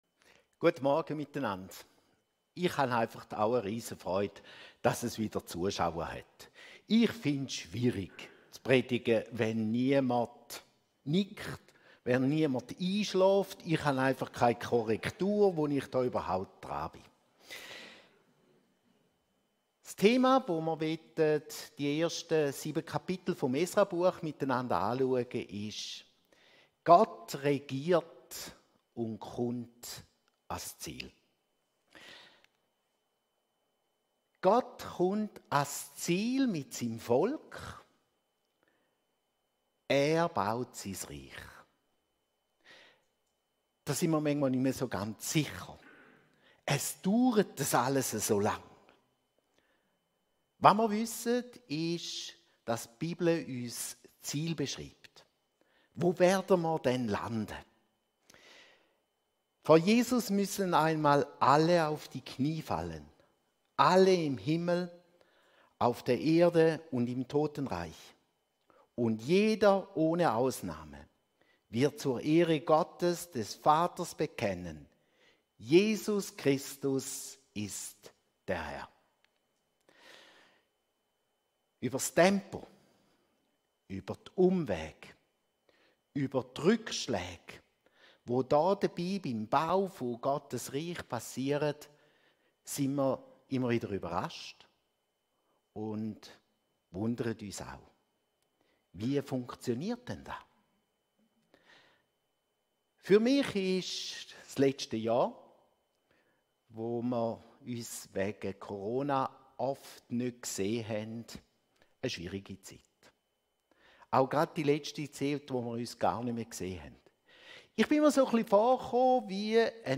210307_predigt.mp3